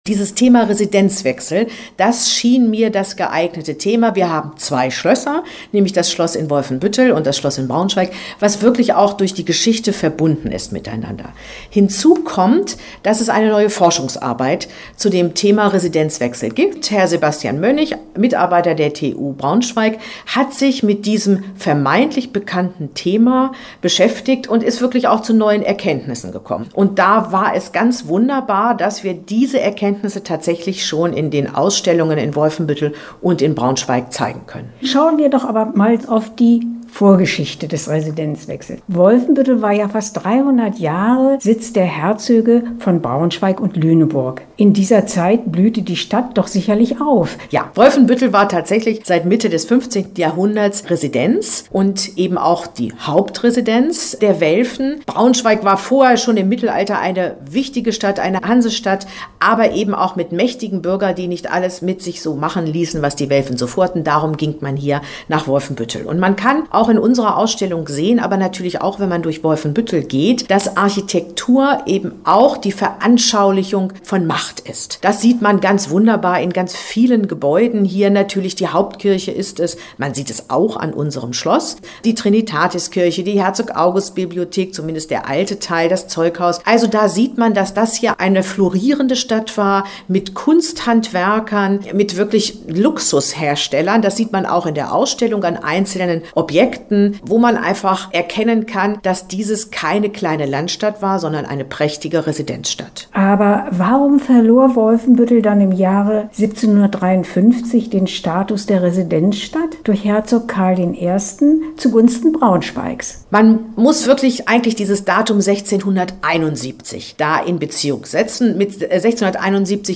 Interview-Residenzwechsel-Schlossmuseum-WF.mp3